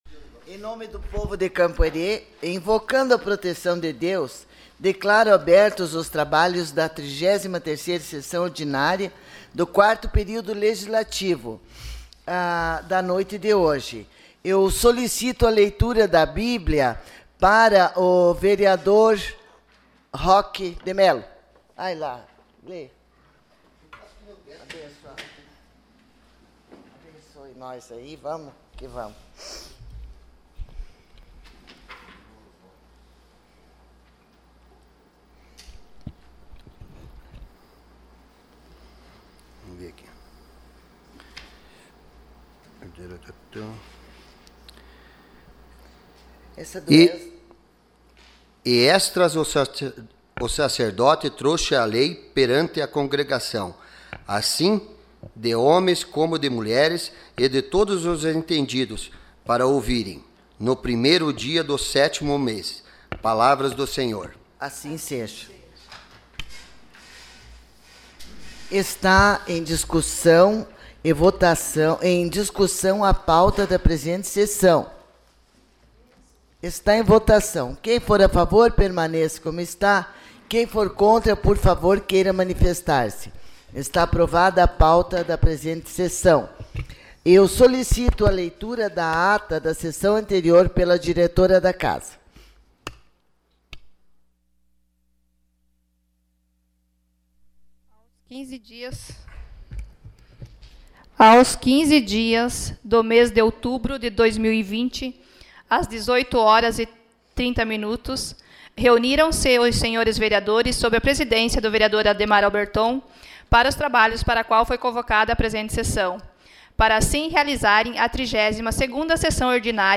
SESSÃO ORDINÁRIA DIA 26 DE OUTUBRO DE 2020